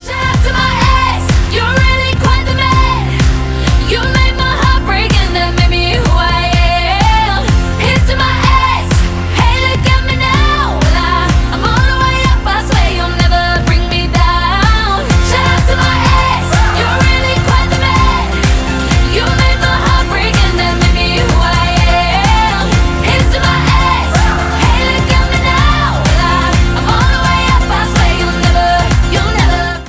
• Pop
British girl group